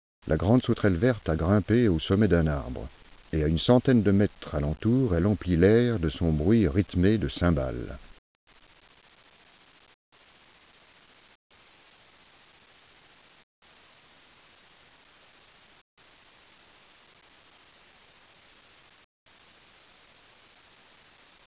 L'été, l'adulte se rencontre dans les buissons ou dans les arbres où les mâles se perchent sur les plus hautes branches pour striduler.
de la Grande Sauterelle verte
durée de la séquence de chant : environ une seconde.
Le chant, puissant (audible à 50 mètres), consiste en une phrase hachée avec des accents émis à une cadence rapide. La stridulation rappelle un peu le bruit d'une cymbale.